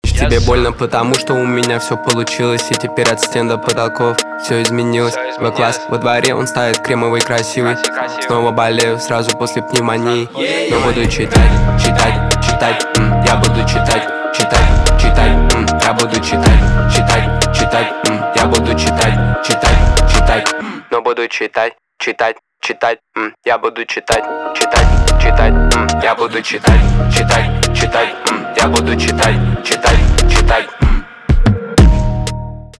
русский рэп
басы , качающие
пианино